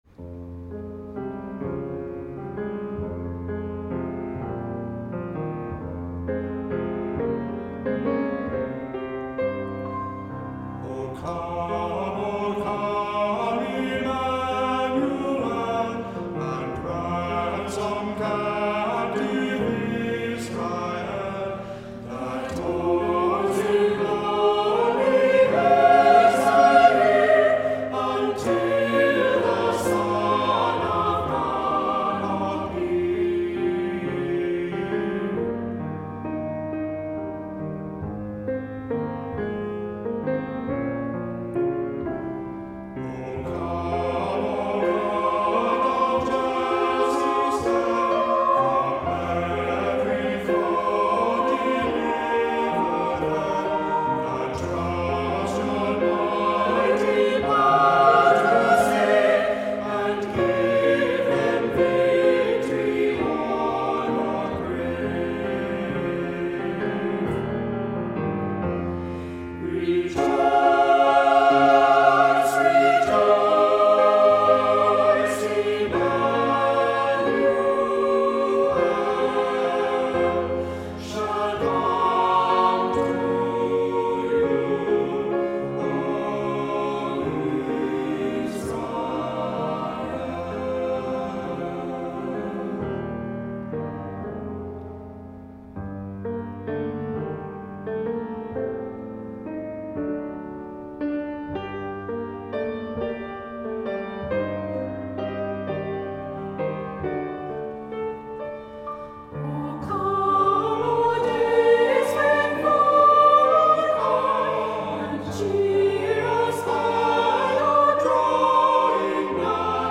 Voicing: "SATB"